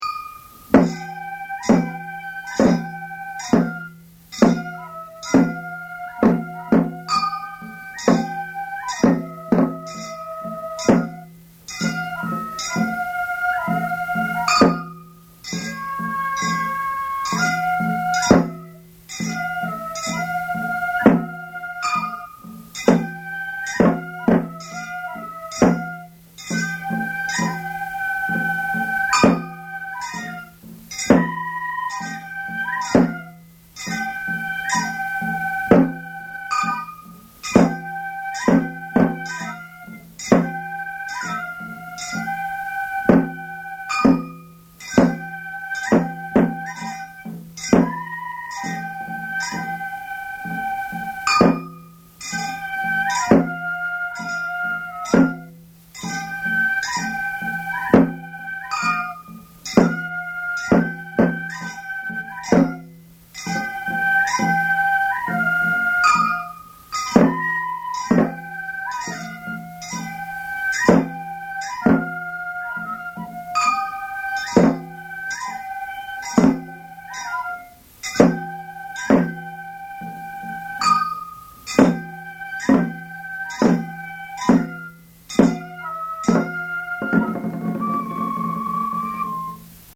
千鳥 「流し」と曲はほとんど一緒だがゆっくりと演奏する。
太鼓
笛
鉦
昭和62年11月1日　京都太秦　井進録音スタジオ